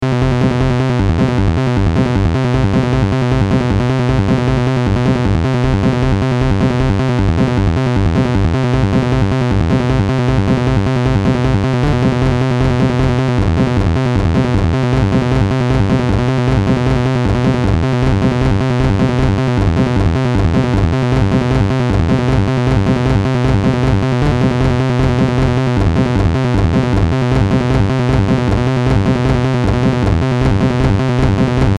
心拍の速さを加速させるような緊張感漂うサウンドと、リズミカルなパルスが組み合わさり、あなたの日常を瞬時に刺激します。